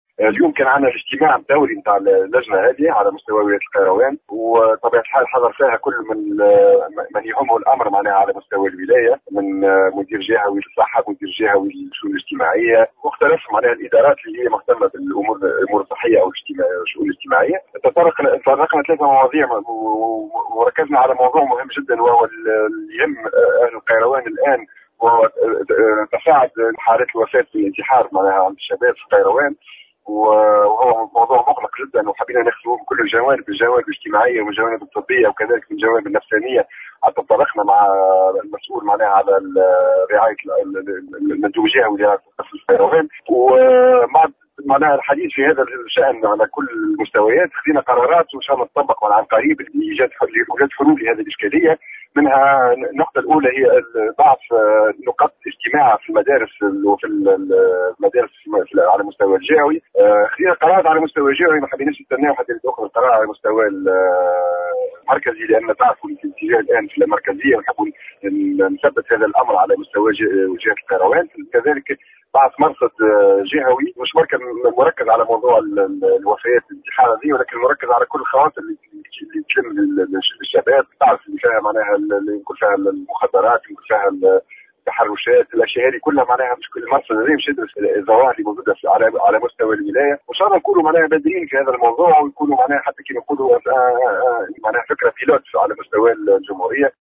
تصريح